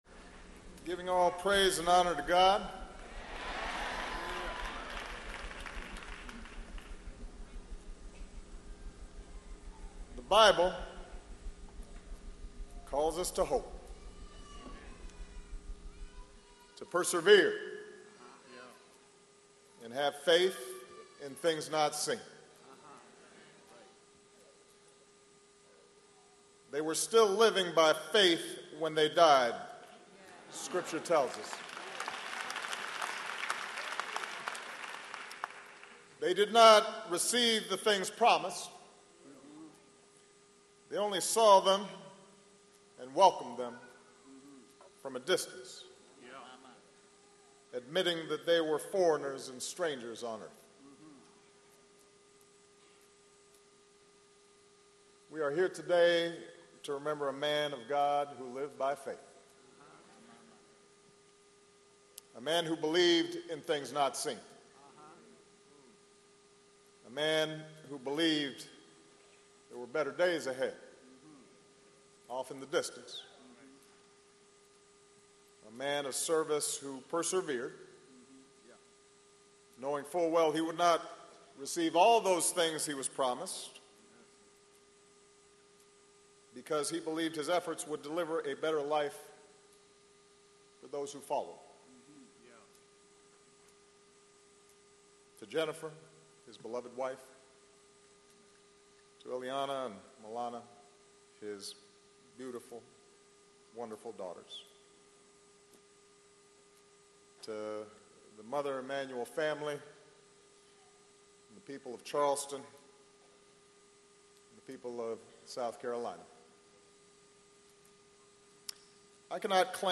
Obama Delivers Eulogy At Funeral For Slain Charleston Pastor
The president, who led those mourners in a rendition of "Amazing Grace," said the alleged killer failed in his effort to "terrorize and oppress."